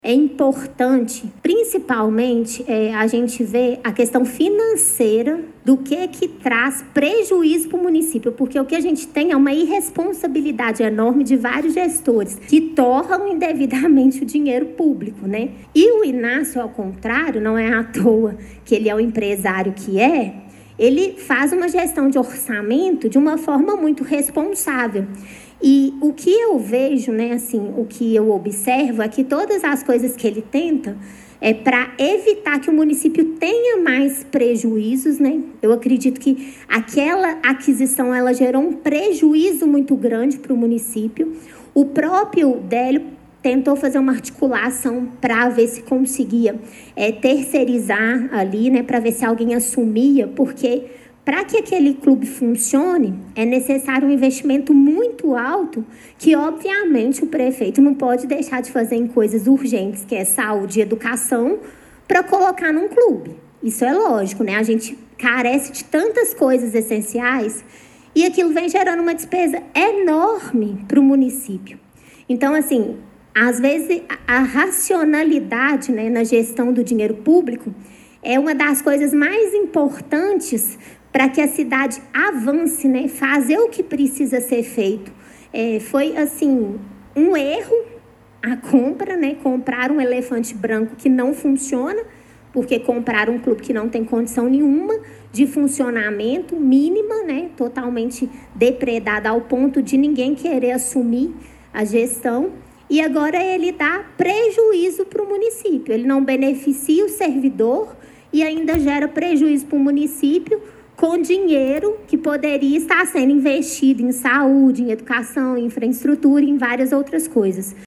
Em contrapartida, a vereadora Márcia Flávia Marzagão Albano (PSDB) utilizou a tribuna para defender a venda, classificando a manutenção do clube como um peso financeiro insustentável para os cofres públicos.